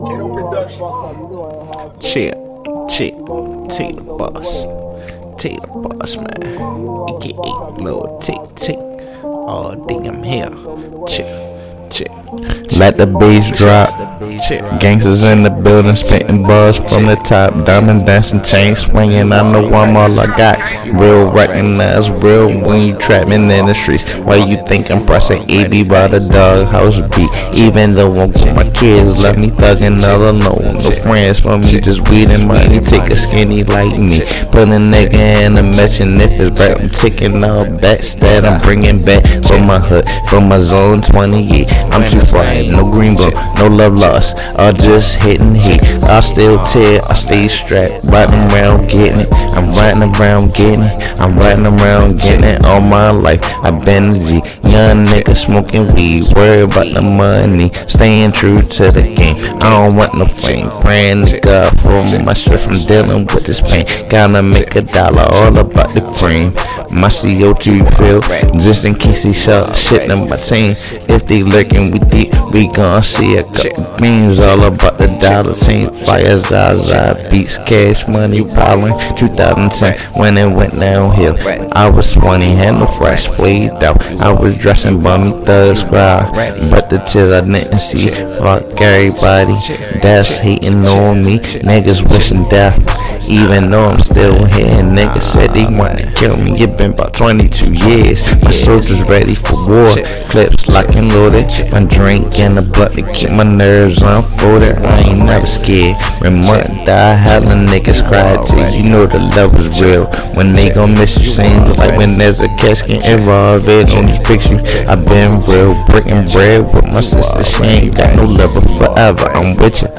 It's Freestyled